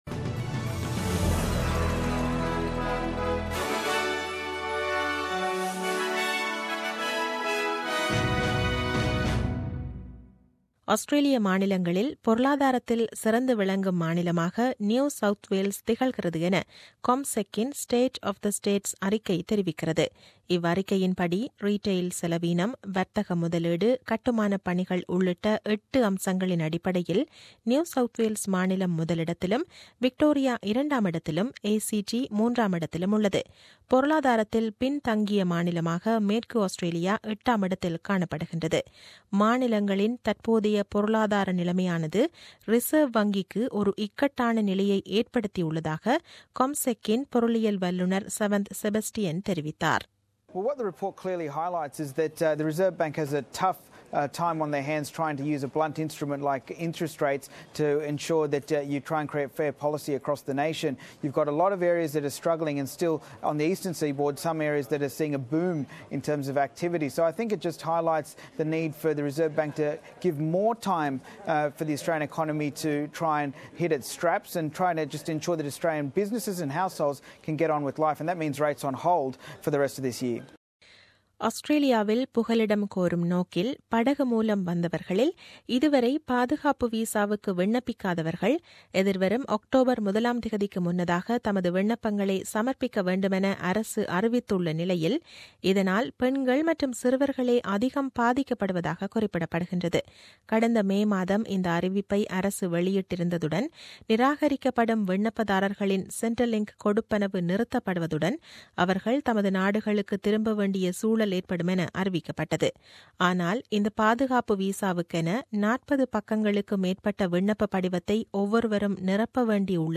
The news bulletin aired on 24 July 2017 at 8pm.